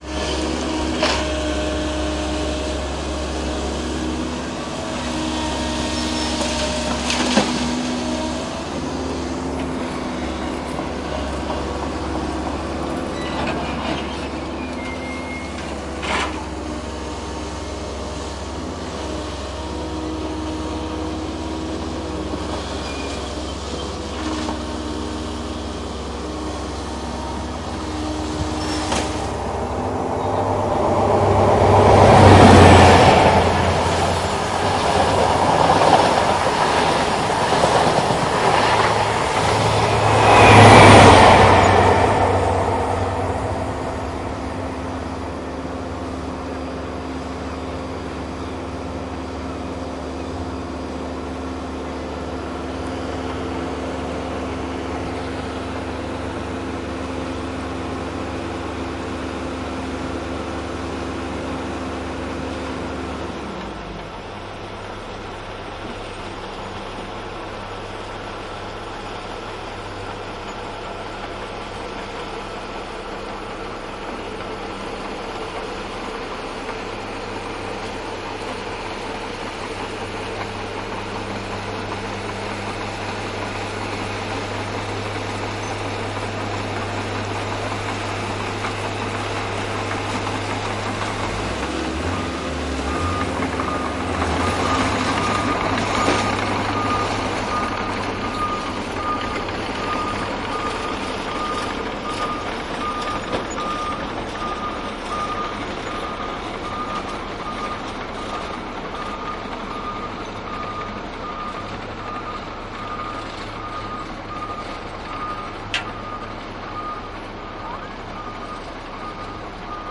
铁路维修工程
描述：卡迪夫的轨旁维修工作。挖掘机卸载压载物。斯旺西到帕丁顿快车通过R> L。推土机压载镇流器，接近RL并通过报警RL反转。复杂的复合工业音景，具有显着的冲击和噪音元素，并且在列车的进近和通过时具有强烈的戏剧性元素。用Zoom H2n录制，在Wavelab中编辑。没有处理。
Tag: 挖掘机 铁路 机械 电机 机械 噪声 机器 建设 挖掘机 现场记录 工业 推土机 发动机